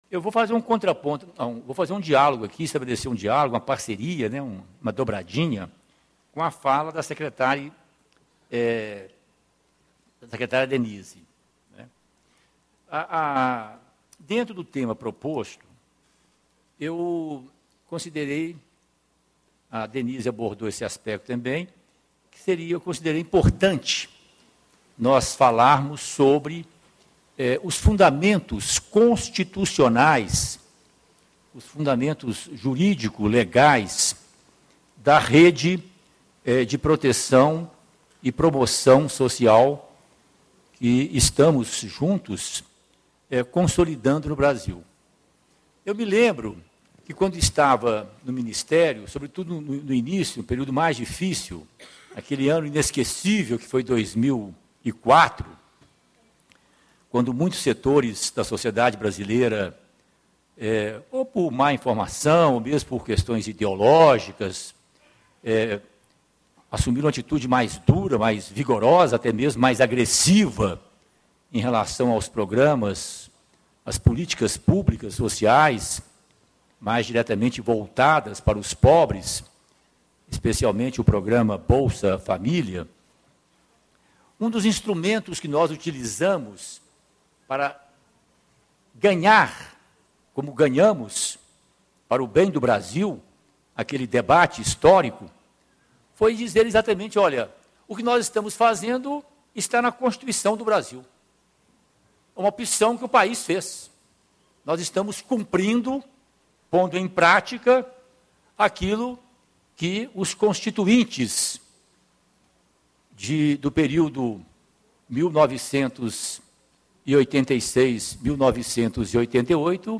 Patrus Ananias, Professor da PUC-Minas e ex-Ministro de Desenvolvimento Social e Combate à Fome
Discursos e Palestras